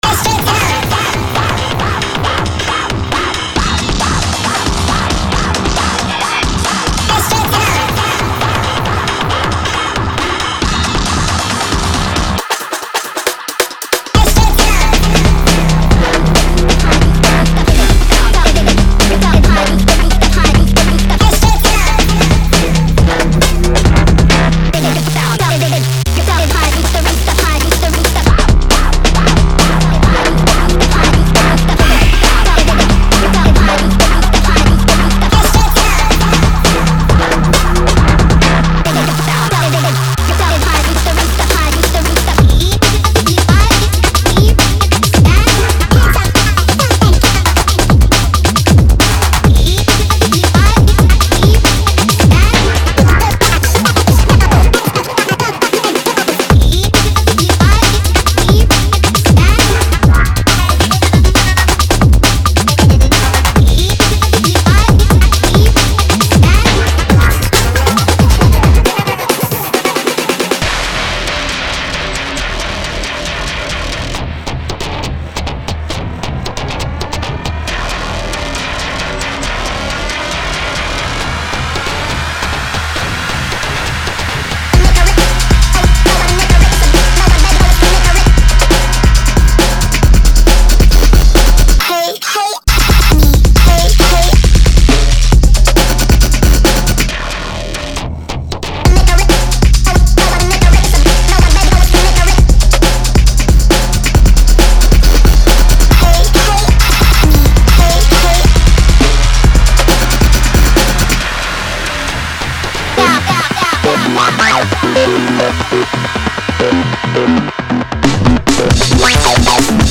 しかも、かつてないほどハードに、ファンキーに、力強く。
90年代ジャングルのドラムのスワッガーが、現代クラブ仕様の136 BPMで生まれ変わる様を。
レコード棚の奥から見つけ出した煙たくグリッティな雰囲気が、今やクリアでシネマティックなサウンドとして蘇る様を。
デモサウンドはコチラ↓
Genre:Breaks
136 BPM